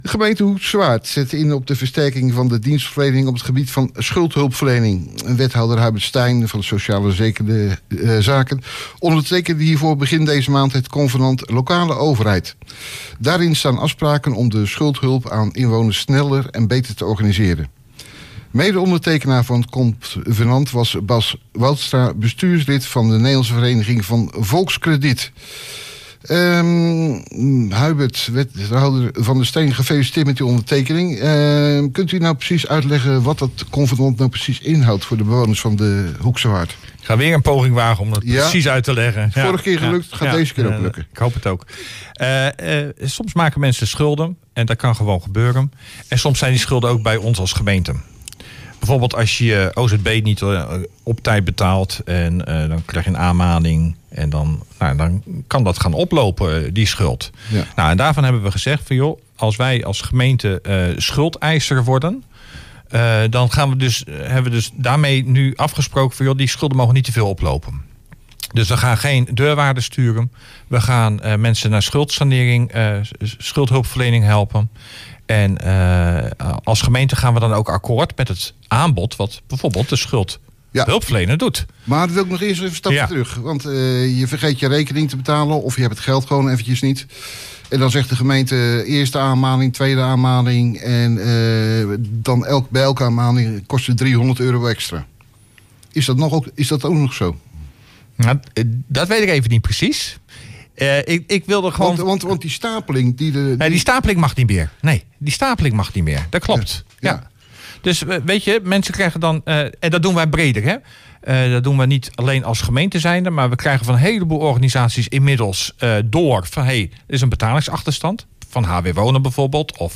In het programma Hoeksche Waard Actueel spraken wij met de wethouder over het convenant Lees het artikel Convenant Schuldhulpverlening verder op Omroep Hoeksche Waard.